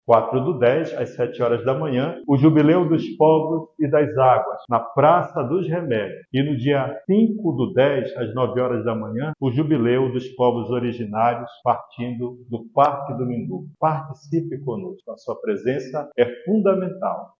O Bispo Auxiliar de Manaus, Dom Hudson Ribeiro, reforçou a importância desses momentos de união, destacando a vigília e as atividades como uma oportunidade para fortalecer a voz do povo amazônico no cenário global.
SONORA-2-DOM-HUDSON-.mp3